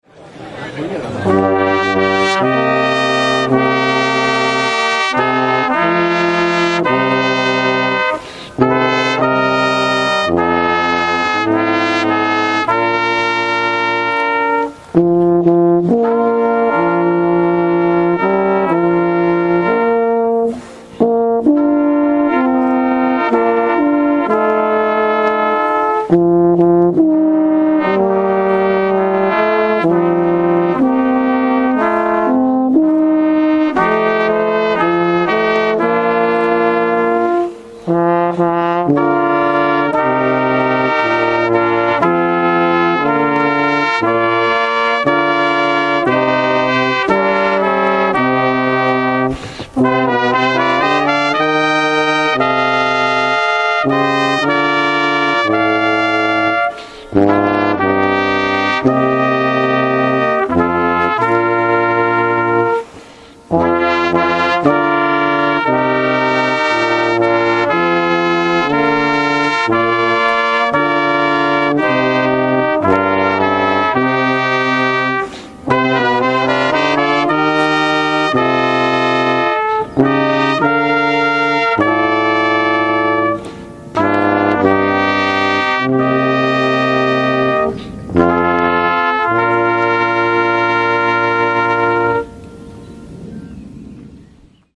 Ob štirinajsti obletnici ene najhujših nesreč v zgodovini slovenskega gorskega reševanja je bil danes, 10. junija 2011, spominski pohod na Okrešelj.
slika z nastopa kvarteta trobil Policijskega orkestra, Turska gora, 2011Slovesnost so pospremili zvoki žalostink kvarteta trobil Policijskega orkestra.